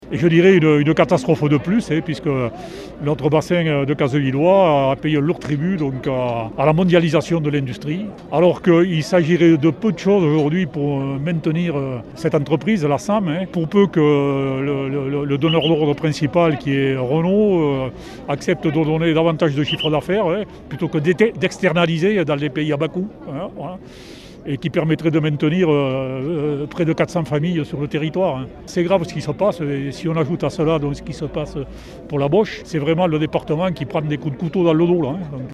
Les salariés de SAM Industries manifestaient à Rodez le 11 mars 2021.
son-manifestation-rodez.mp3